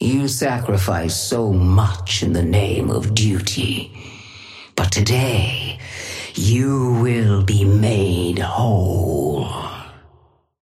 Sapphire Flame voice line - You sacrifice so much in the name of duty, but today you will be made whole.
Patron_female_ally_haze_start_04.mp3